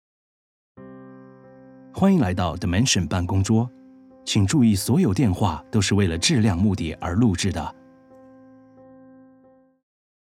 Commercial, Young, Natural, Friendly, Warm
Telephony